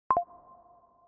Новое письмо в электронной почте